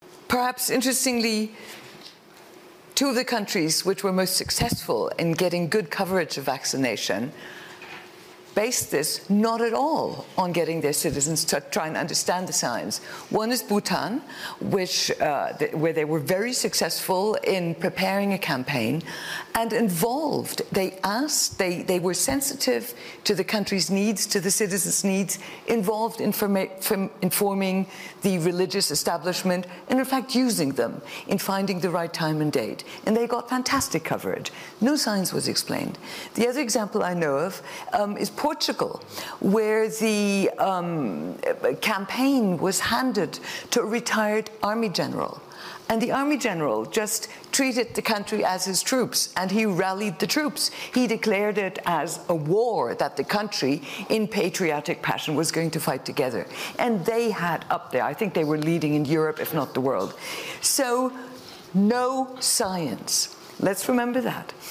Beim WEF hat man darüber philosophiert, wie man Menschen zur Impfung bringt, ohne die lästige Wissenschaft erklären zu müssen. Hier hört man Maria Leptin, Präsidentin des Europäischen Forschungsrats, in einer Podiumsdiskussion des Weltwirtschaftsforums in Davos zum Thema "Der Status der Pandemie".